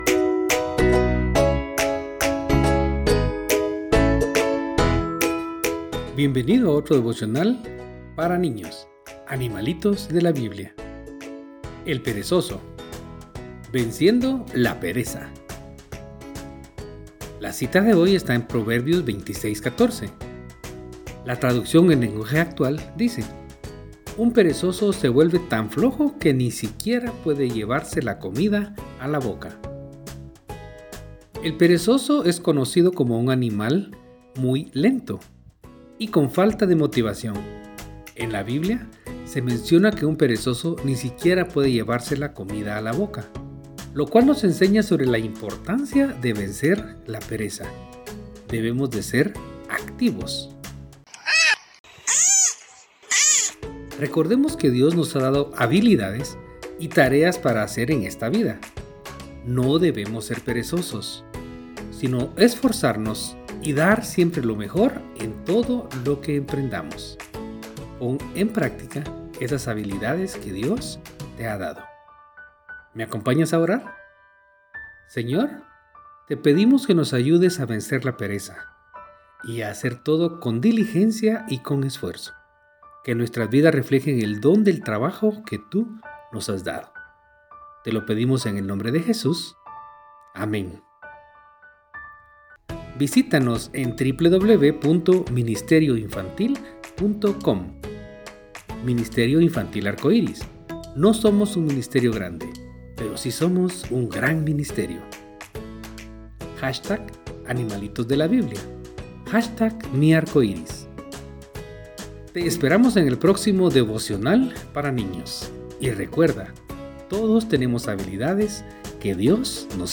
Animalitos de la Biblia – Devocionales Cortos para Niños